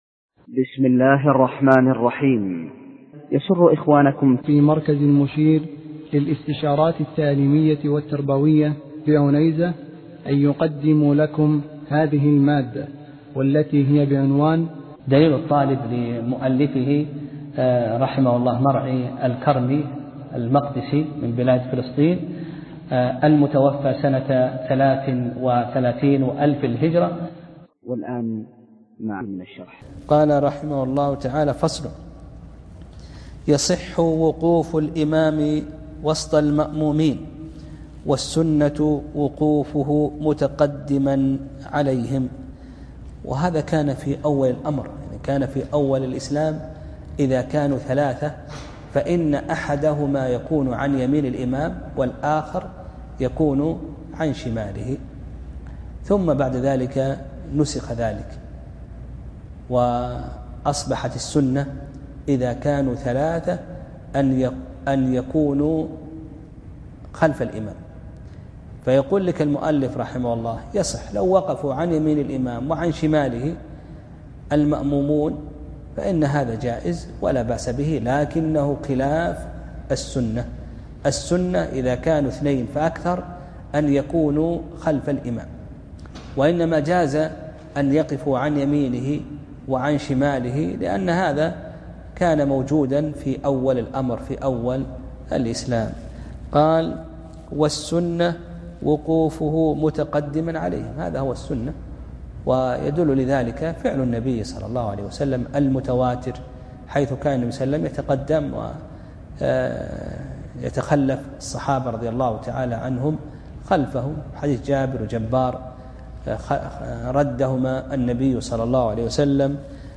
درس (13) : فصل في الإمامة (3)